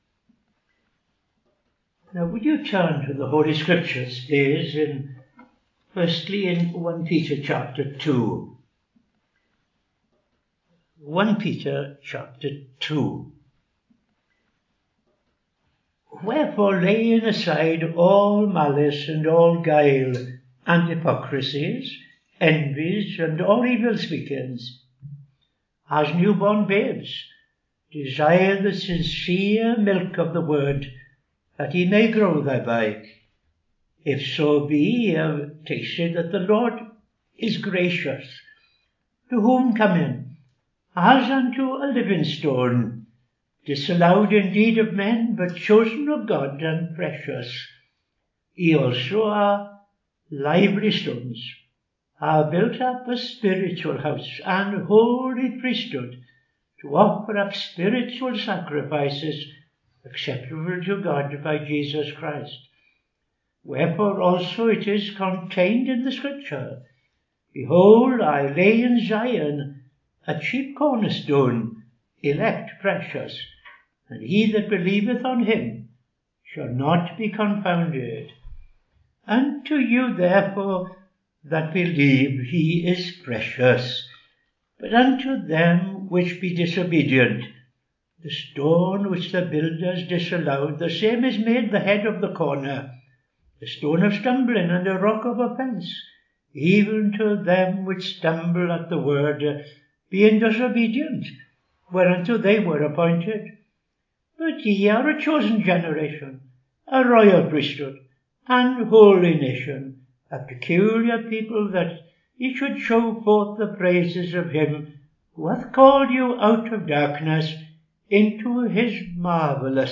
Reading I Peter 2:1-10; II Timothy 4:18